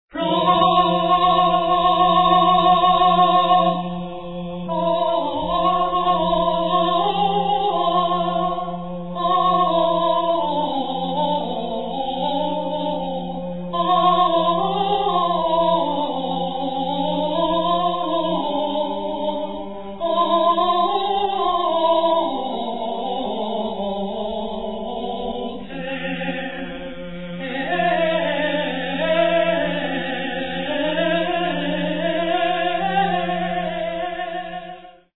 countertenor
tenors
viol
gradual